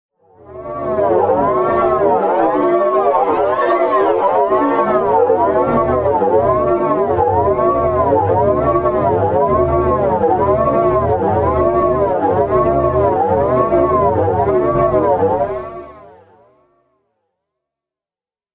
Звуки вихря, круговорота